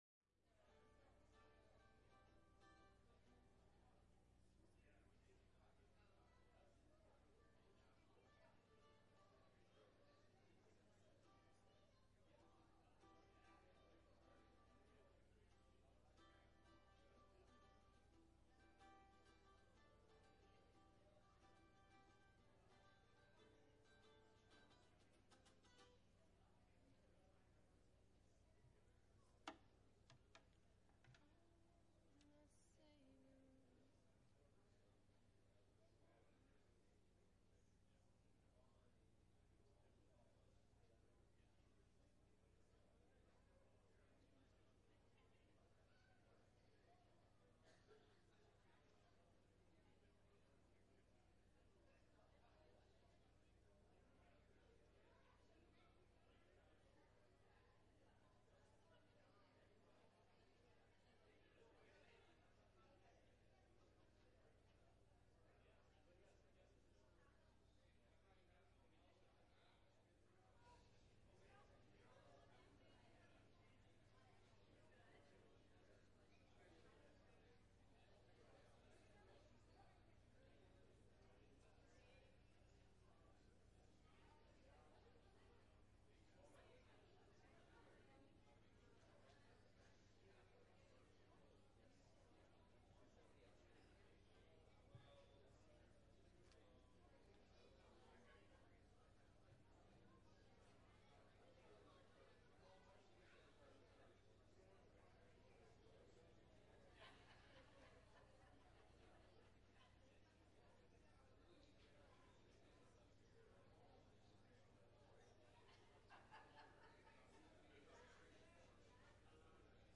9_26-21-Sermon.mp3